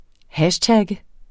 Udtale [ ˈhaɕˌtagə ]